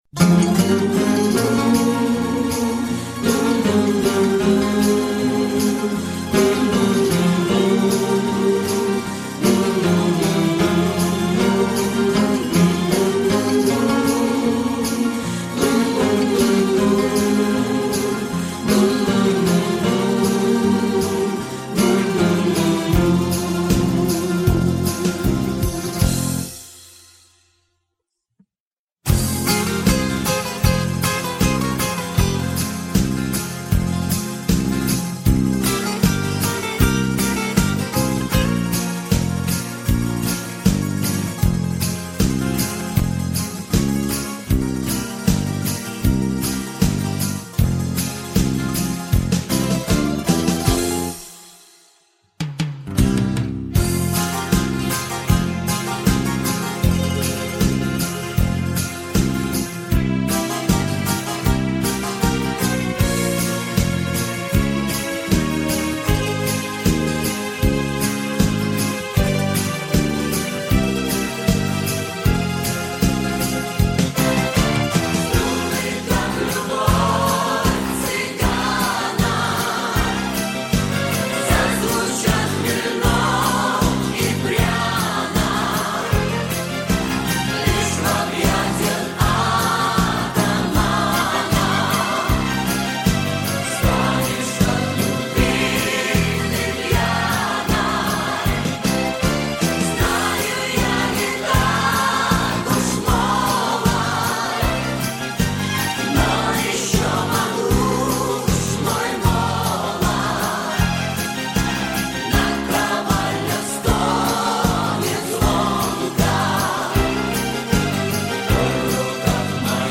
цыганские мелодии для души и фона